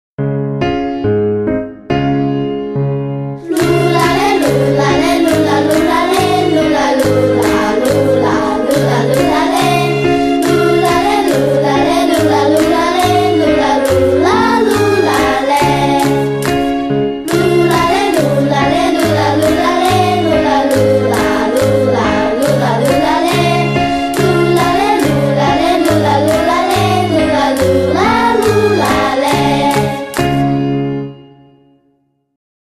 Campfire Songs